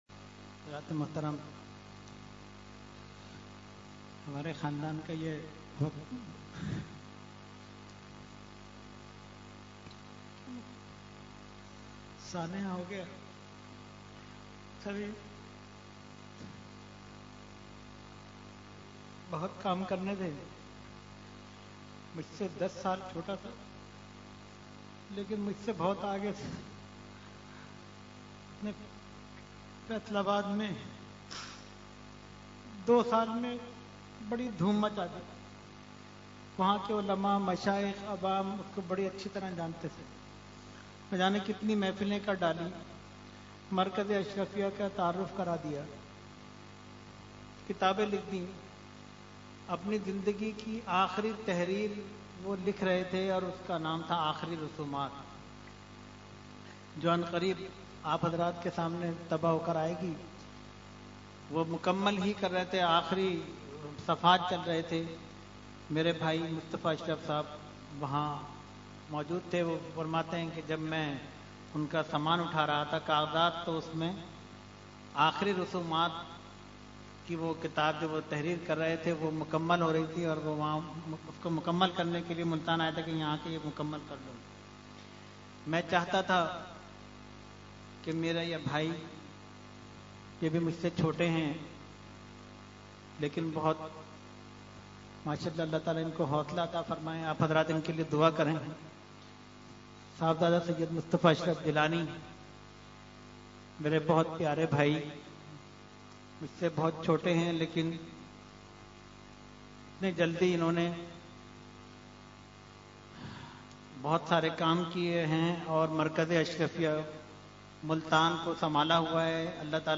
Category : Speech